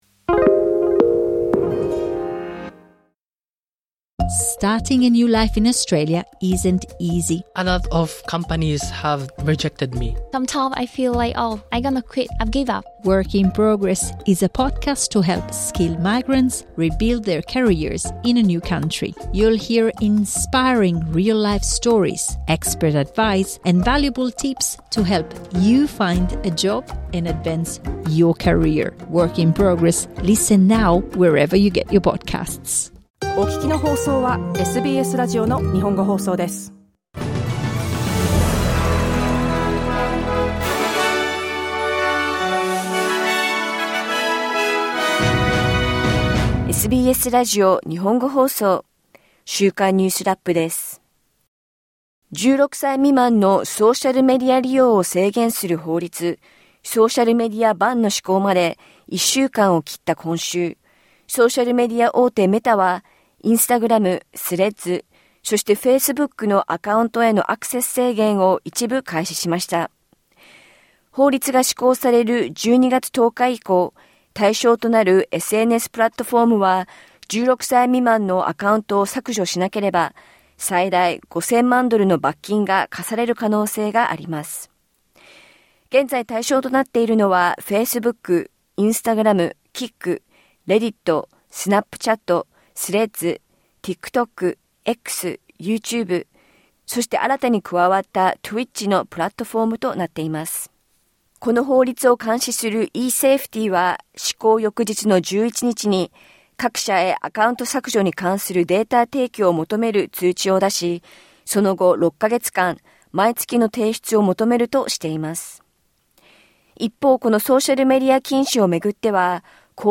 欧州最大級の国別対抗ソングコンテスト「ユーロビジョン」の来年の大会をめぐり、ヨーロッパ放送連合（EBU）がイスラエルの参加を認めました。1週間を振り返るニュースラップです。